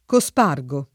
cospargere [ ko S p # r J ere ]